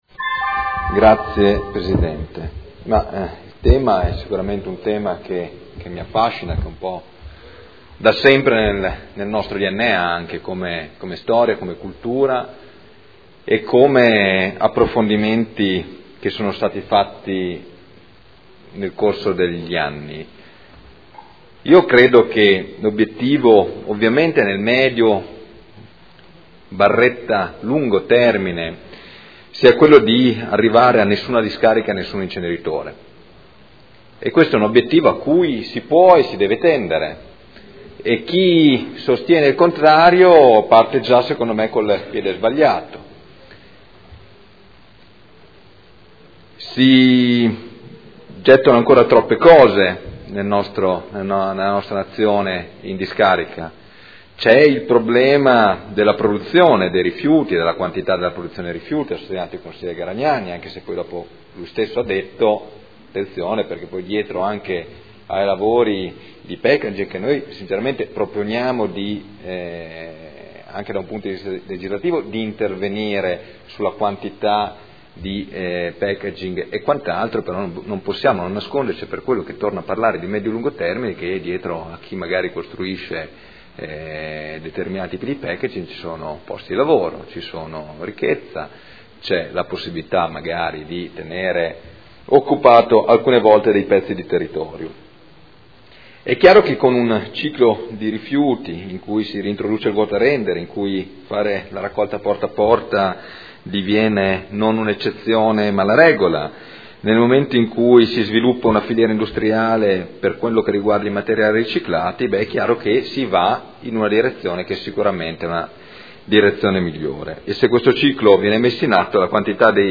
Seduta del 28/11/2013.